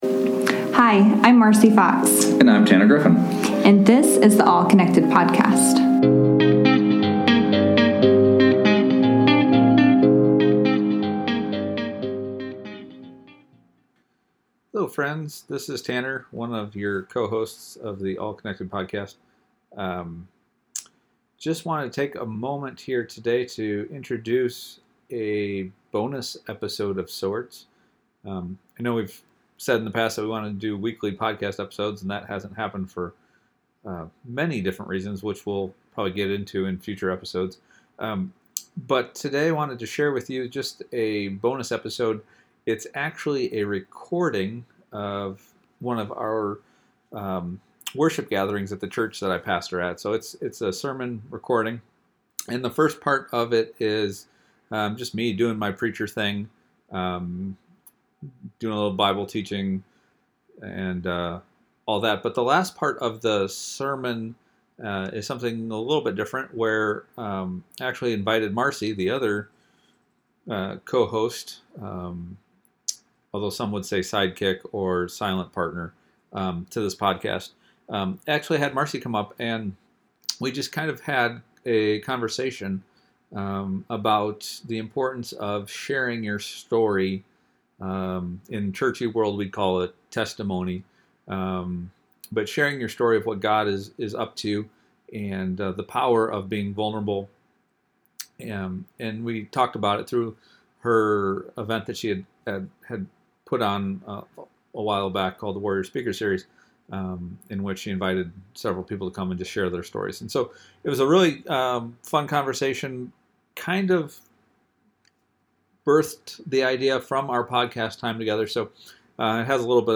The All Connected Podcast went live in church! This episode is the recording of that Sunday morning sermon and discussion.